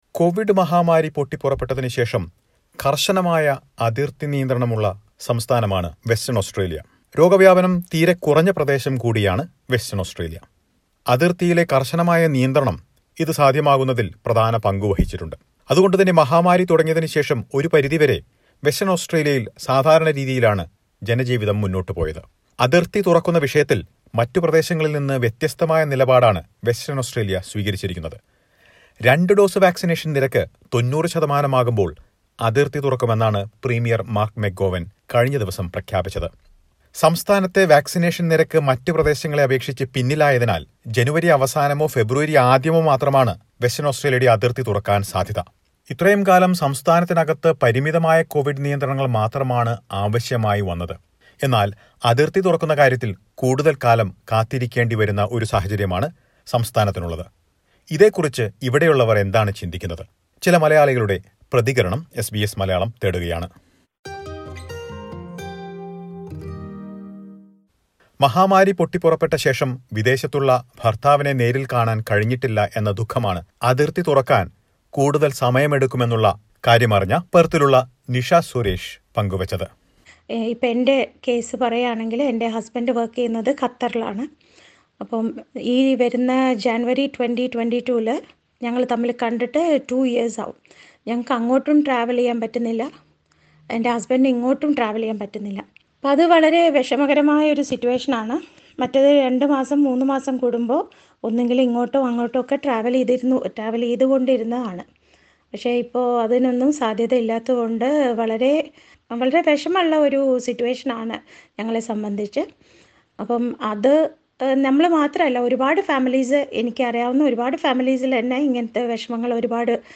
Western Australian residents share their thoughts after the announcement.